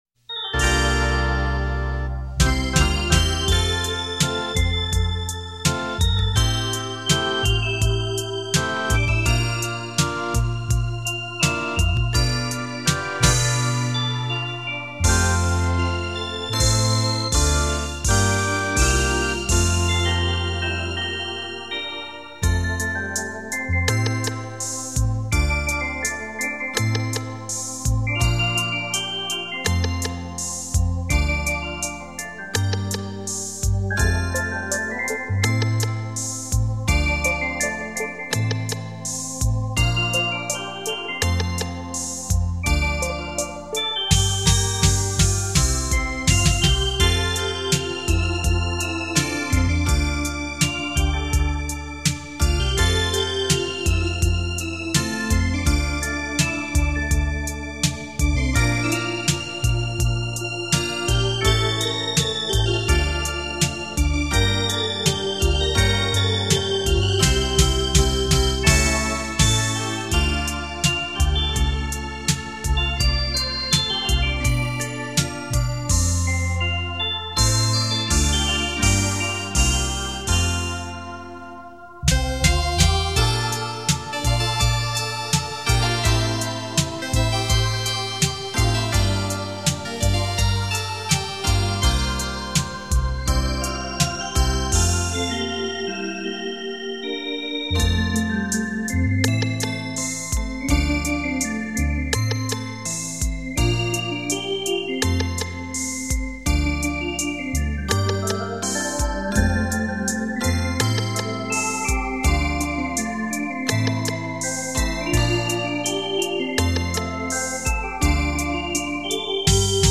电子琴诠释经典 表现出另一种风情
经典的歌曲 全新的演绎 浪漫双电子琴带你回味往事